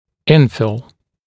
[‘ɪnfɪl][‘инфил]заполнять, наполнять; заполнение